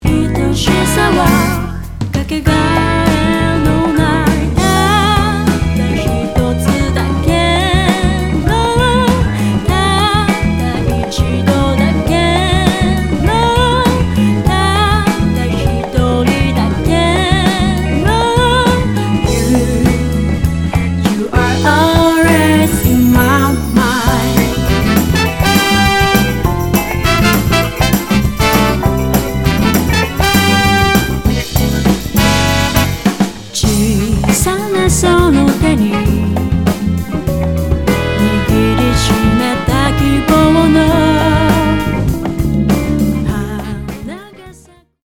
ジャンル(スタイル) JAPANESE POP / JAPANESE SOUL